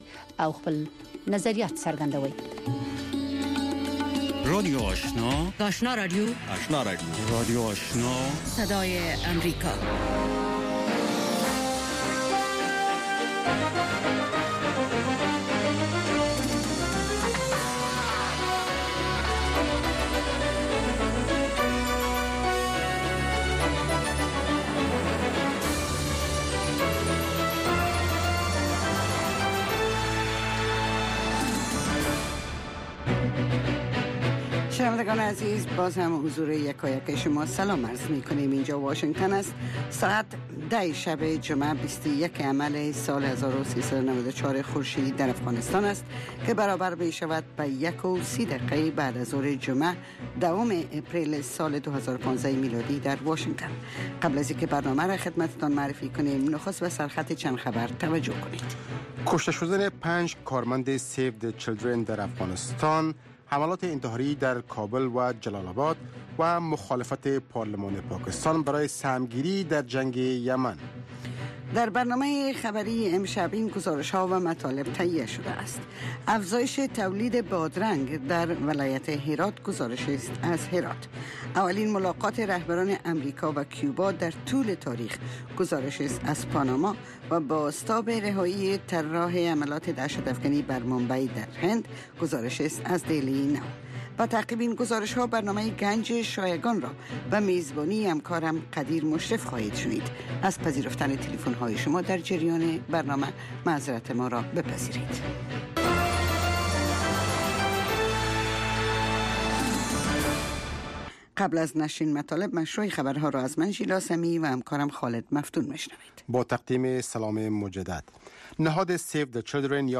برنامه گفت و شنود/خبری اتری - گفتمان مشترک شما با آگاهان، مقام ها و کارشناس ها.